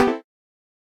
Techmino/media/effect/chiptune/button.ogg at d6ab7e72b2f190afc87fd8d1f8de4242a3d5dc3f
button.ogg